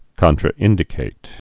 (kŏntrə-ĭndĭ-kāt)